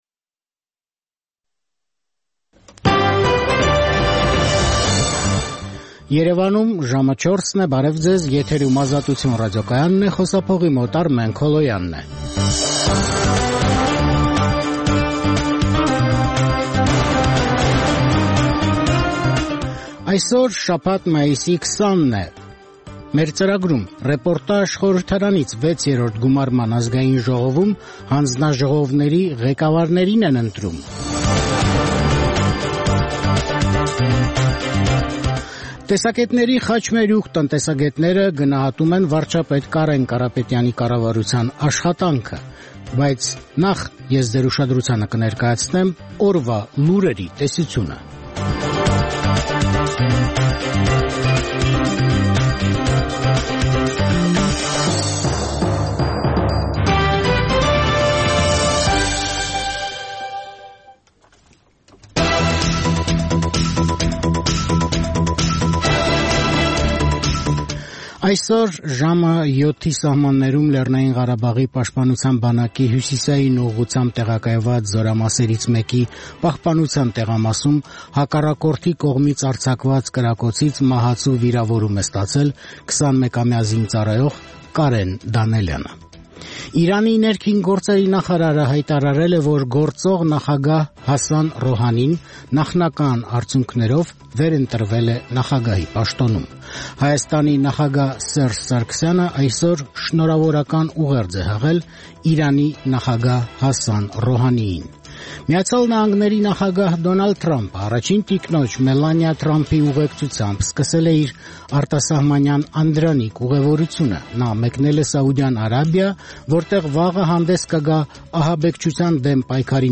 Տեղական եւ միջազգային լուրեր, երիտասարդությանը առնչվող եւ երիտասարդությանը հուզող թեմաներով ռեպորտաժներ, հարցազրույցներ, երիտասարդական պատմություններ, գիտություն, կրթություն, մշակույթ: